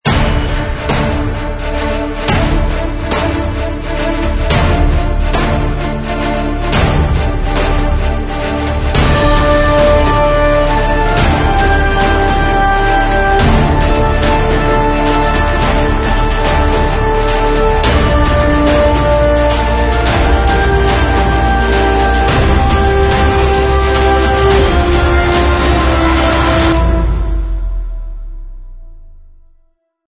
描述：强大的能量史诗项目与大鼓和管弦乐器。